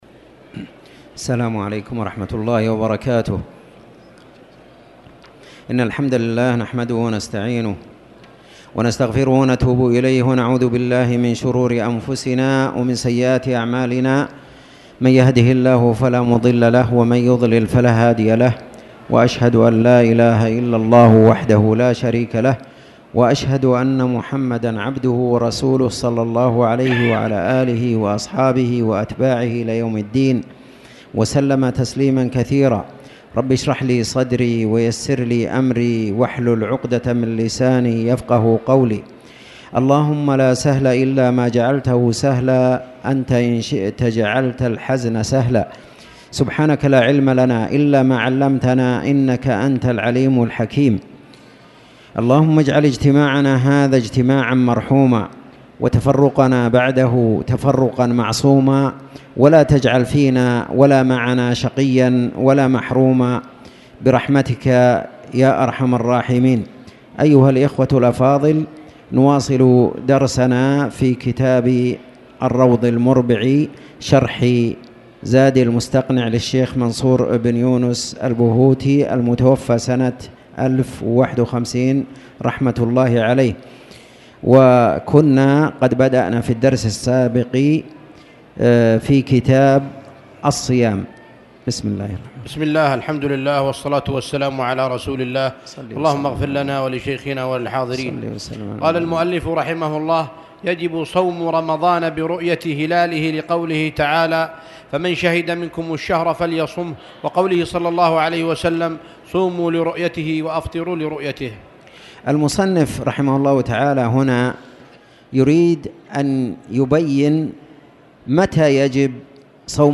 تاريخ النشر ٦ ربيع الأول ١٤٣٨ هـ المكان: المسجد الحرام الشيخ